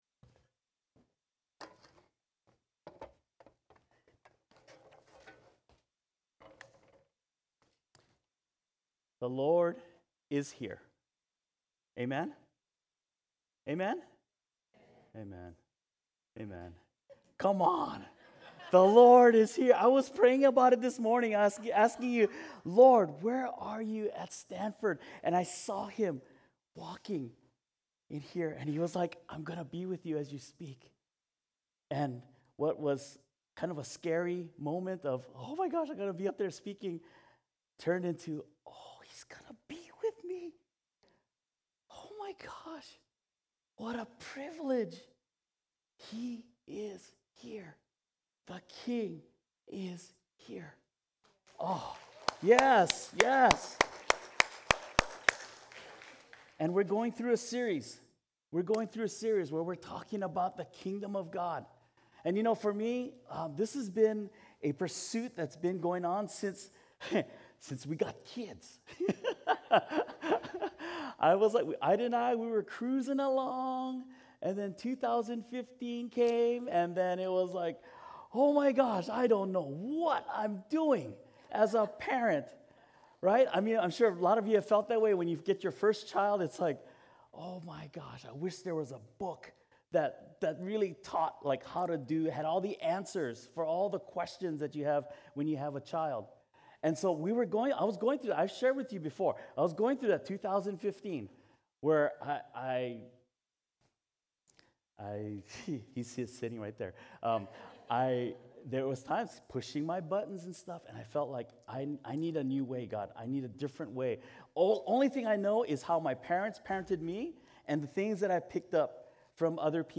Sermons | Catalyst Christian Community